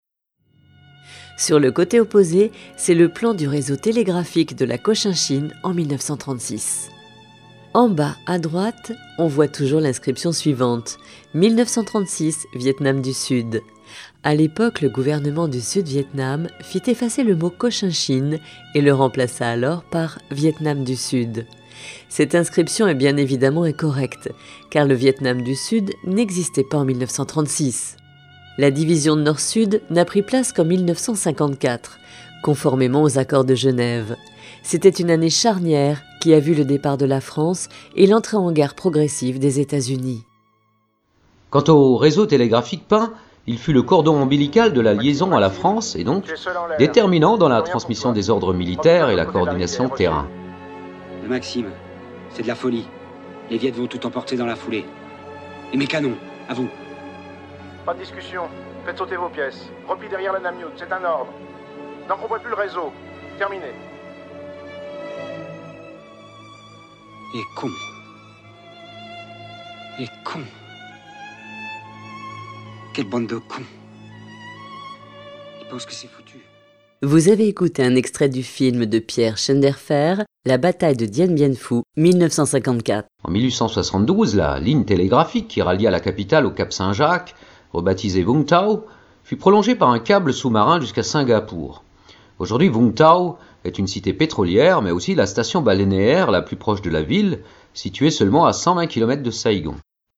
Voix off féminine professionnelle pour pubs, serveurs vocaux, films institutionnels, e-learning, habillages antenne.